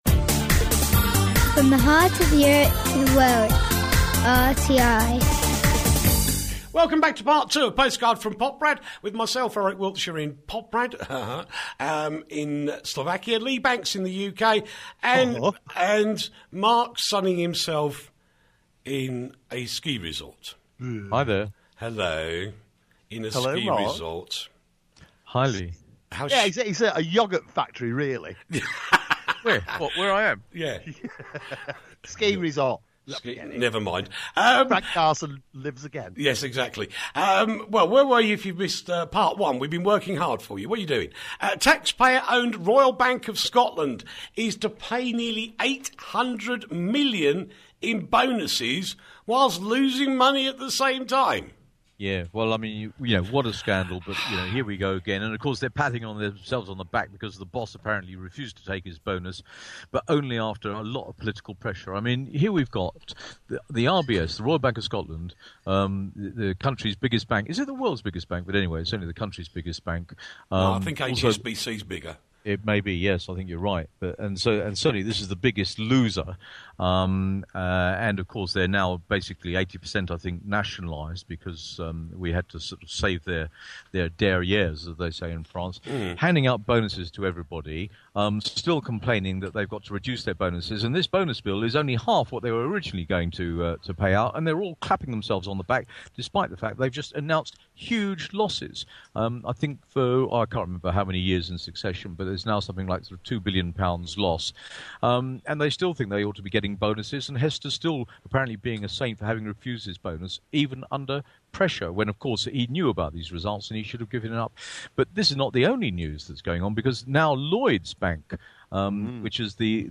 Postcard From Poprad the alternative news show from Radio Tatras International